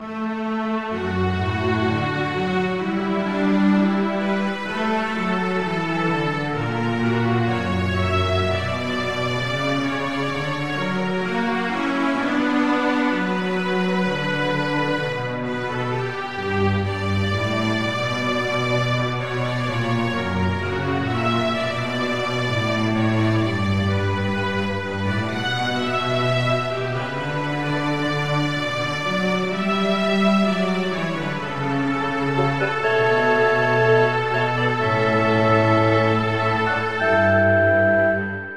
重厚なストリングスアンサンブル。